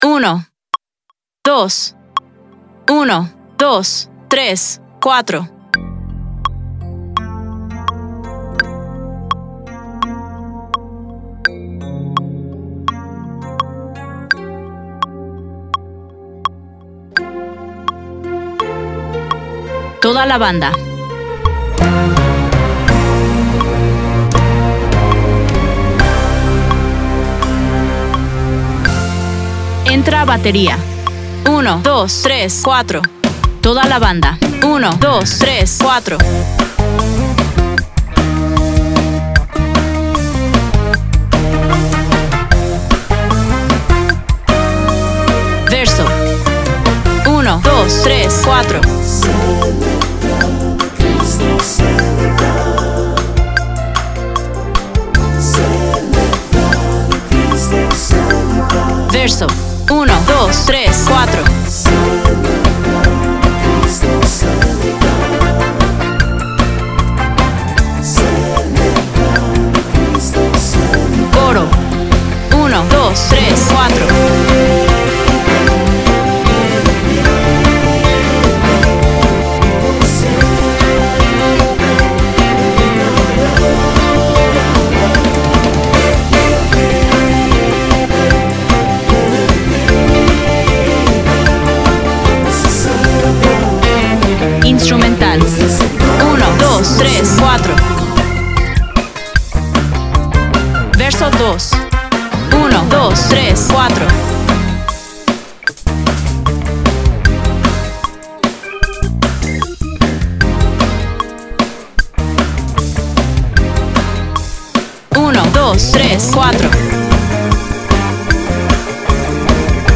DEMO DEL MULTITRACKS